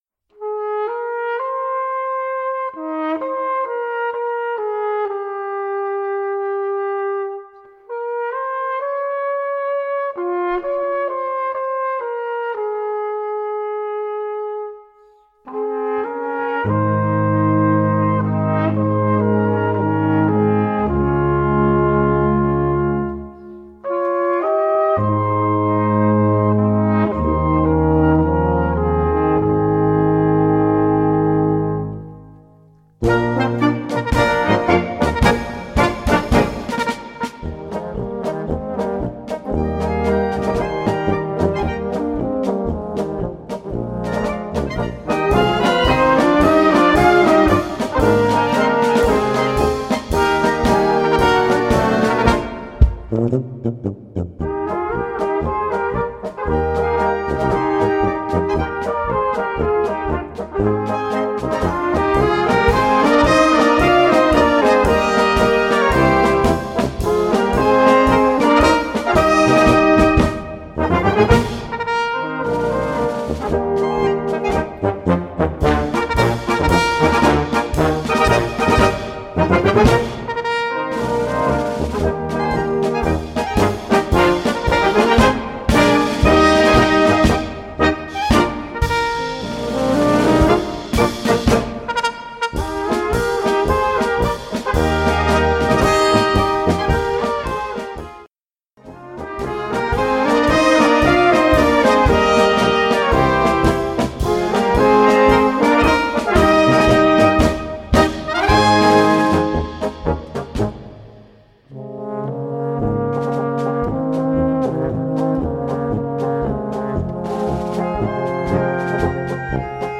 Gattung: Polka (Blasorchester)
Besetzung: Blasorchester